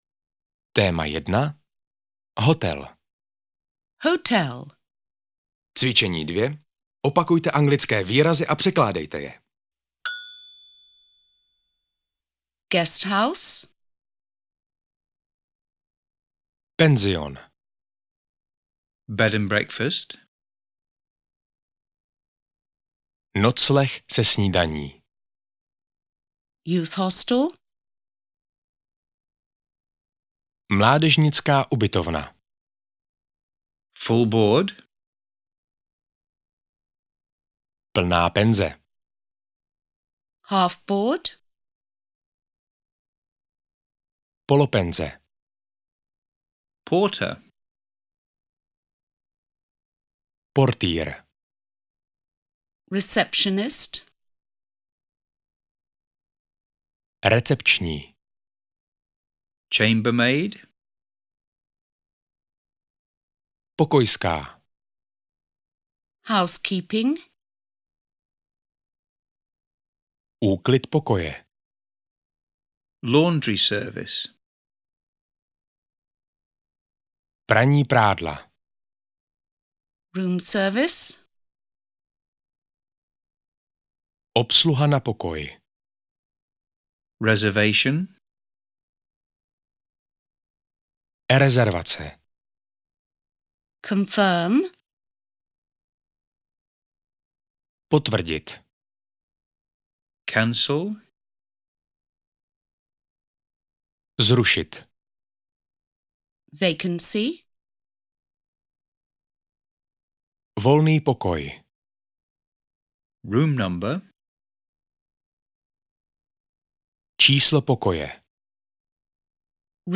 Audio knihaAnglicky bez učebnice - Cestování a služby
Ukázka z knihy